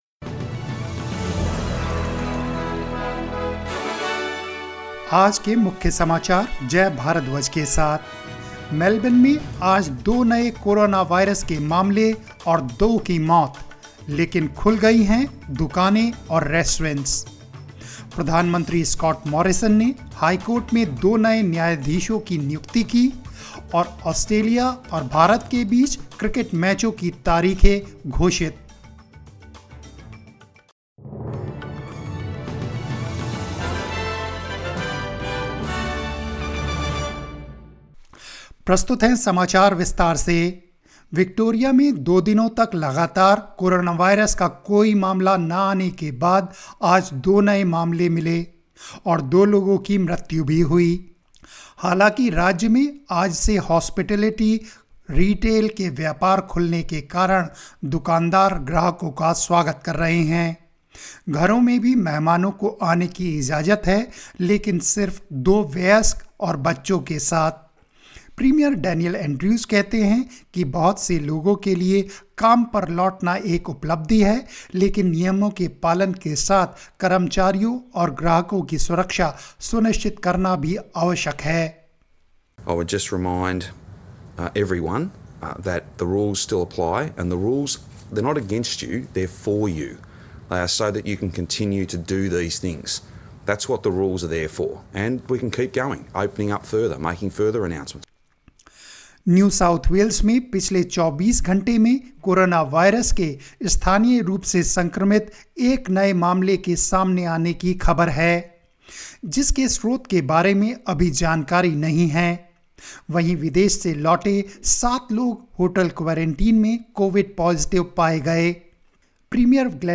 In this bulletin... ** Melburnians head to re-opened shops and restaurants as the state records two new COVID-19 infections and two deaths...